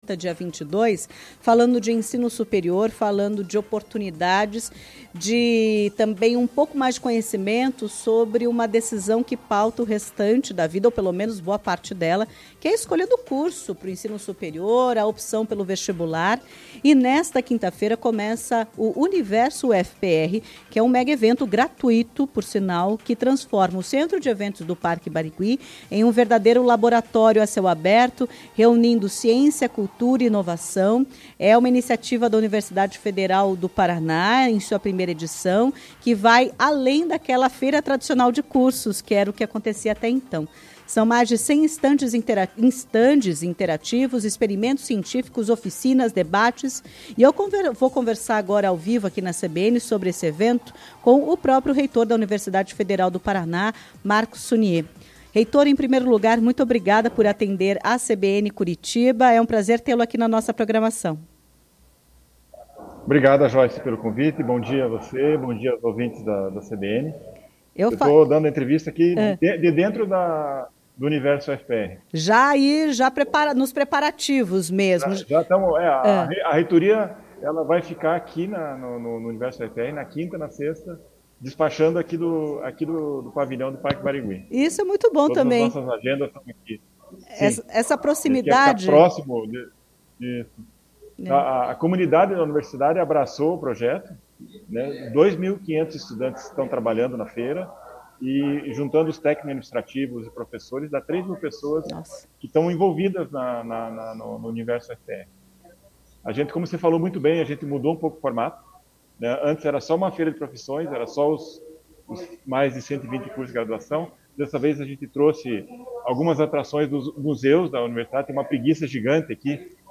O reitor da UFPR, Marcos Sunye, explicou mais sobre a importância da informação para a escolha do curso superior.